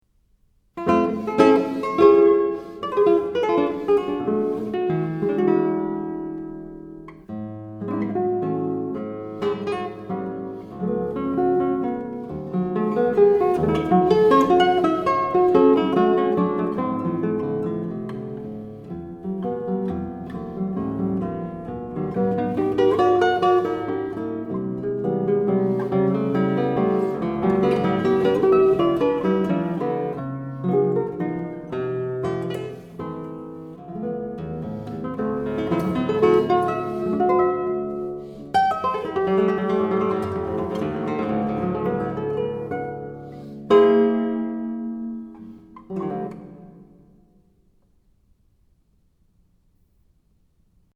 written until 2018 in impressionistic style.
Guitar